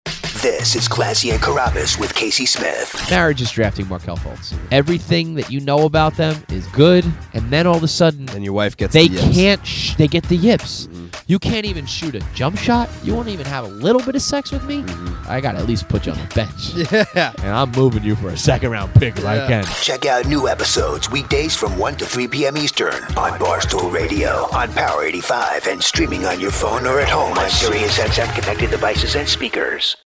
While working at SiriusXM on Barstool Radio, I sometimes put together 30-second spots that are played on other stations to promote the channel.